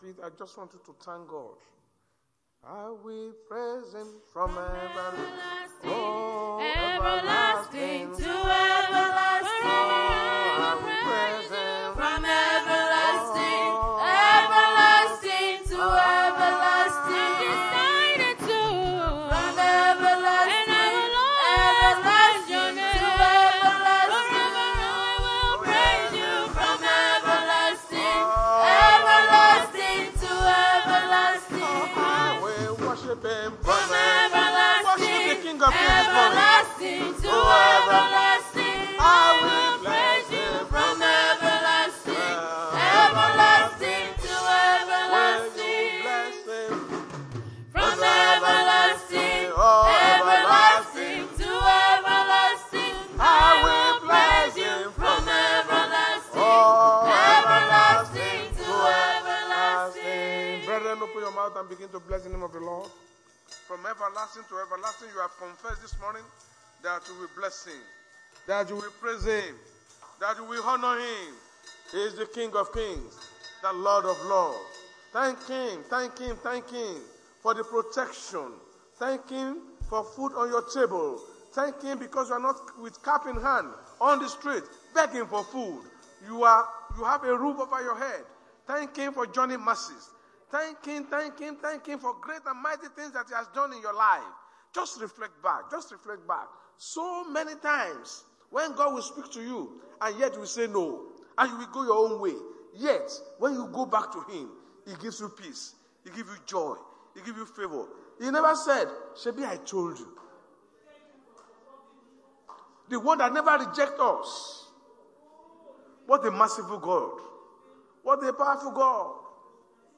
RCCG Sunday Sermon: Overriding God’s GPS